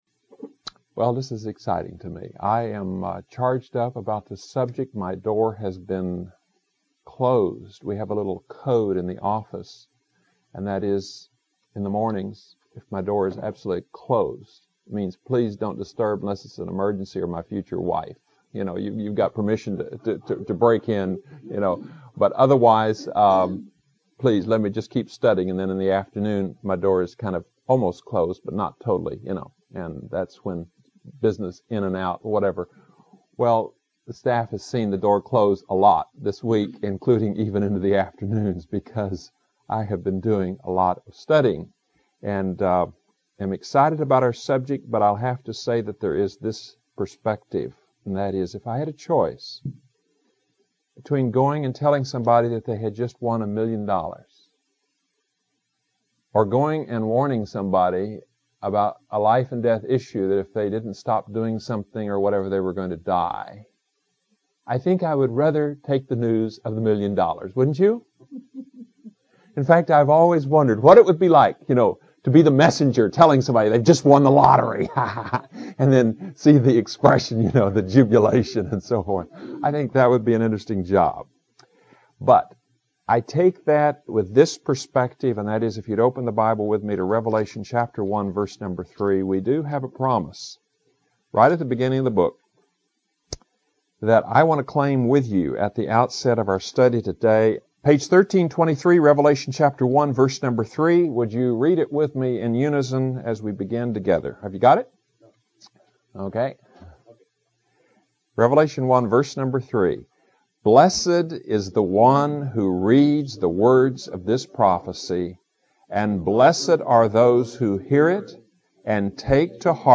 Online Sermon